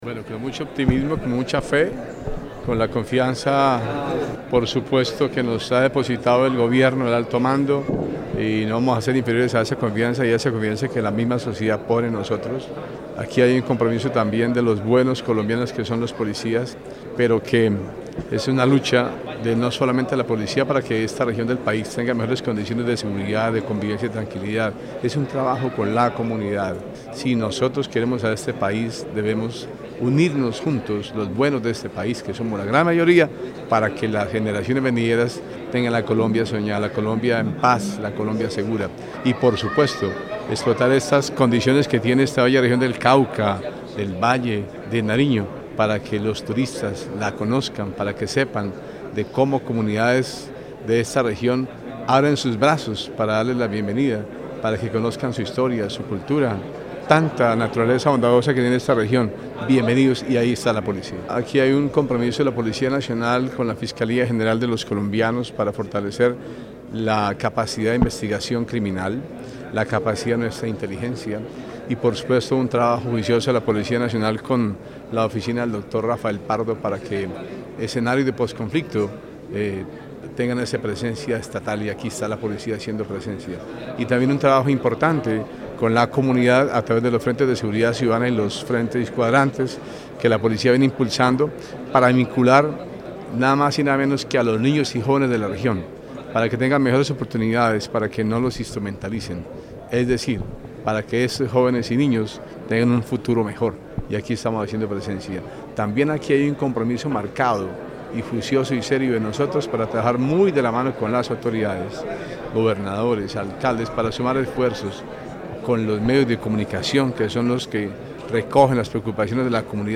FULL_BRIGADIER-GENERAL_WILLIAM-RENE-SALAMANCA_CTE-REGIONAL-IV_PONAL.mp3